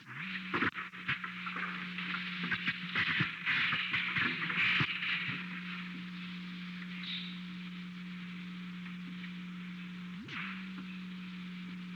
Secret White House Tapes
Conversation No. 607-1
Location: Oval Office
The President met with Alexander P. Butterfield.